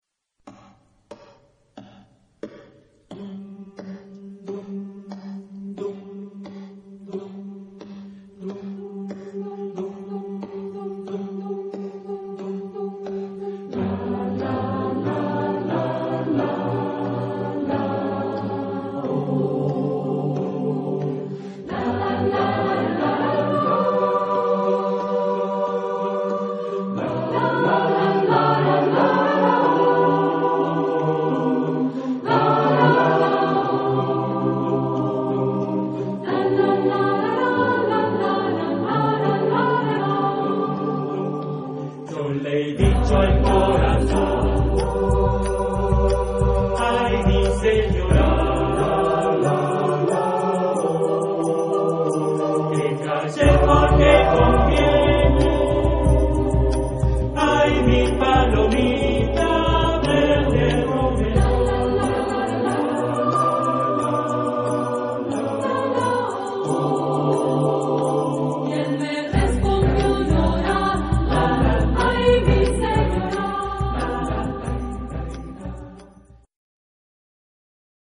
Tipo de formación coral: SATB  (4 voces Coro mixto )
Instrumentos: bombo
Tonalidad : sol mayor